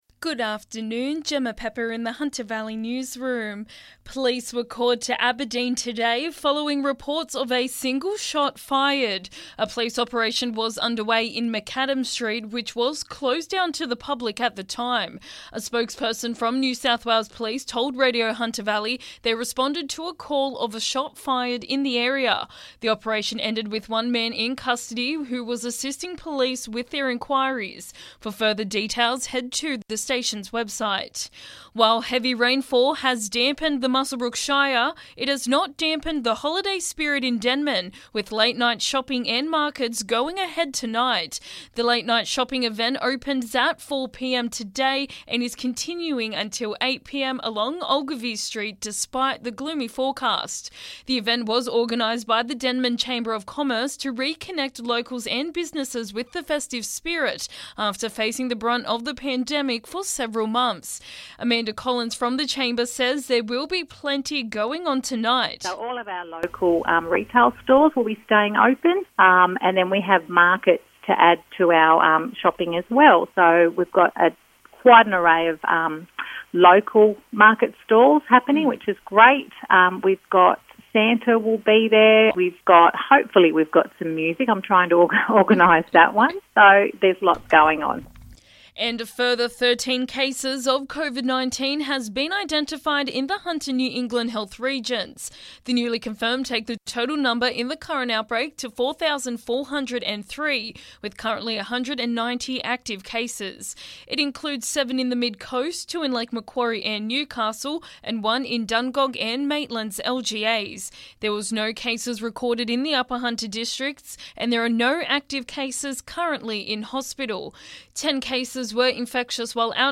LISTEN: Local Hunter Valley News Headlines 9/12/2021